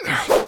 slash_hard.ogg